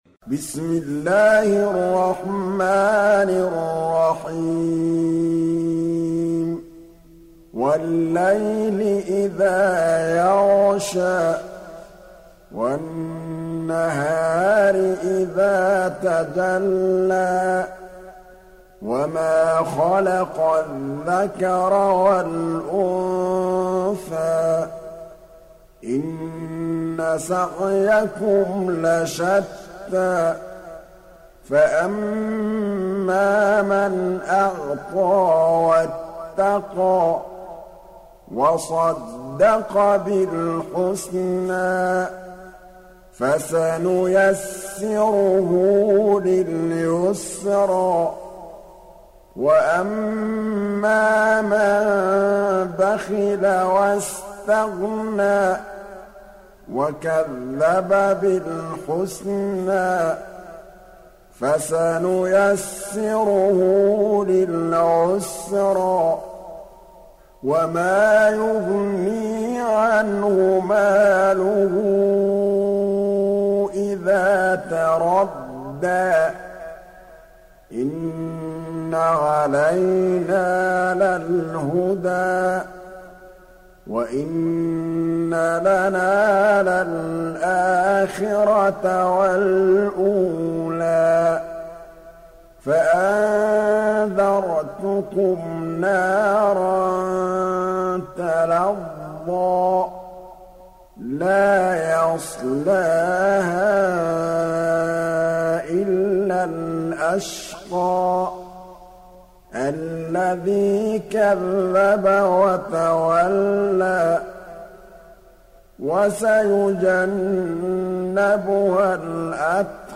Sourate Al Layl Télécharger mp3 Muhammad Mahmood Al Tablawi Riwayat Hafs an Assim, Téléchargez le Coran et écoutez les liens directs complets mp3